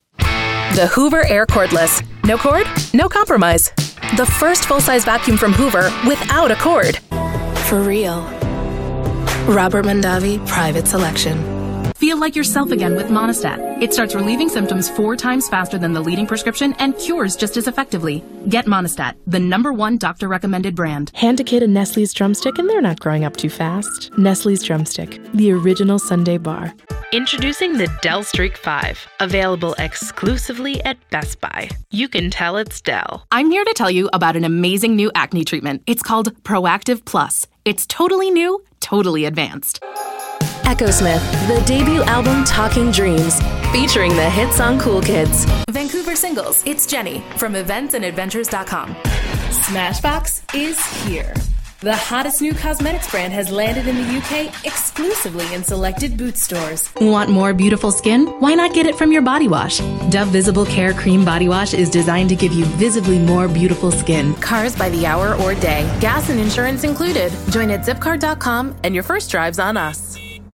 She has a bubbly, upbeat voice which always sounds like she's smiling!
Showreel
Female / 30s / English / American Showreel http